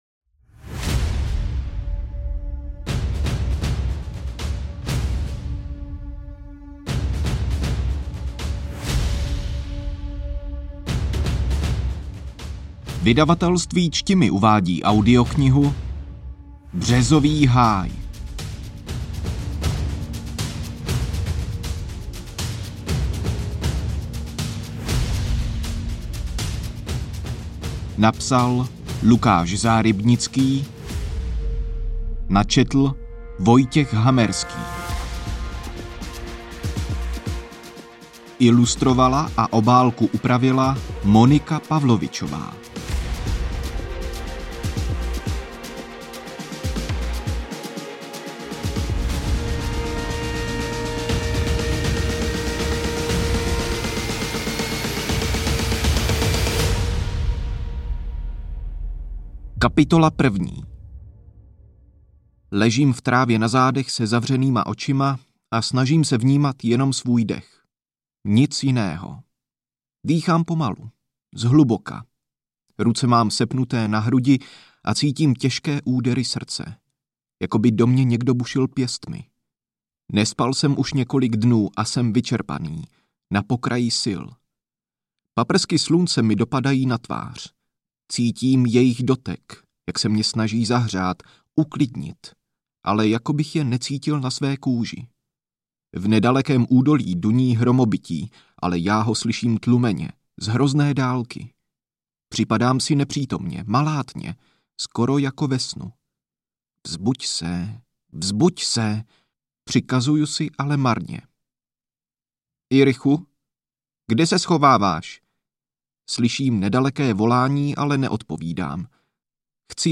AudioKniha ke stažení, 27 x mp3, délka 5 hod. 26 min., velikost 297,2 MB, česky